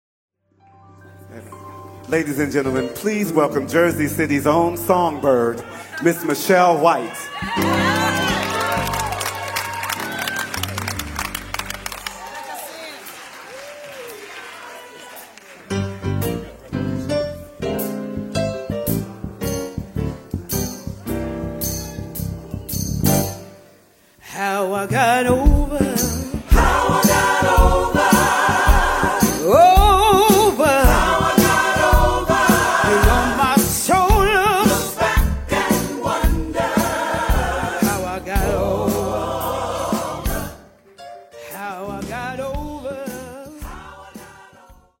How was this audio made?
Live at the South Orange Performing Arts Center.